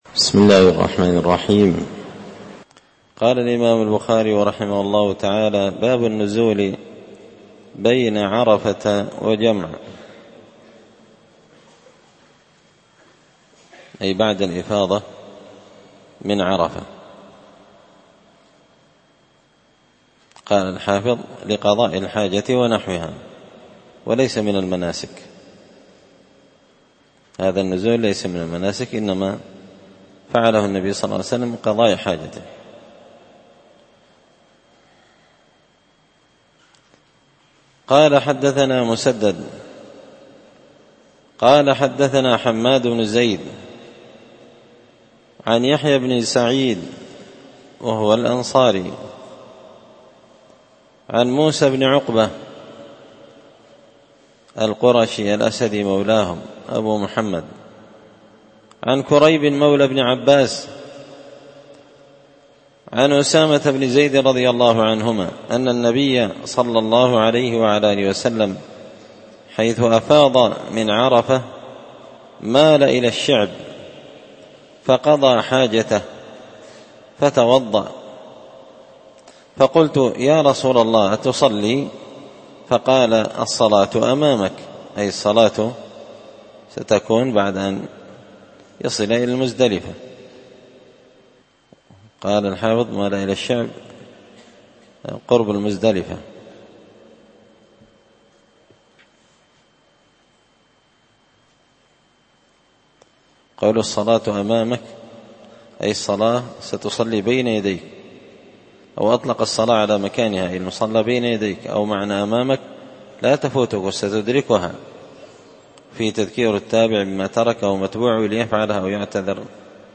ألقيت هذه الدروس في # دار الحديث السلفية بقشن بالمهرة اليمن مسجد الفرقان